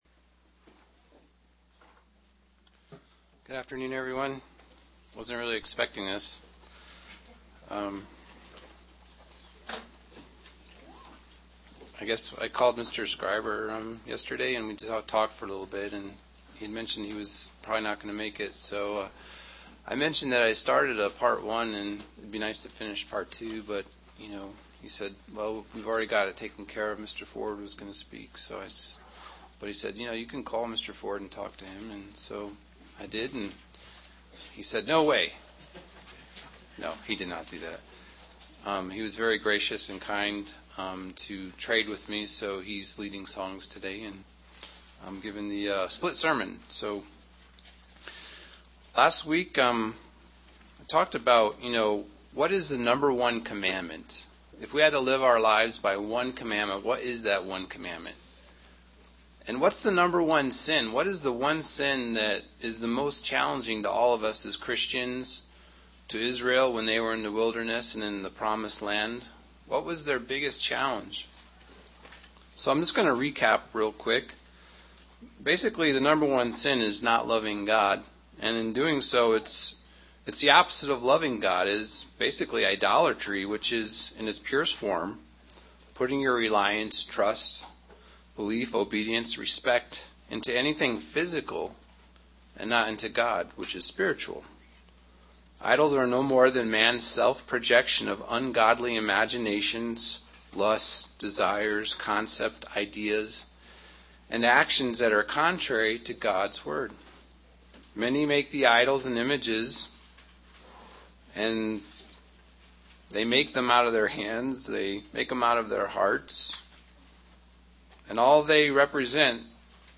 Sermons
Given in Albuquerque, NM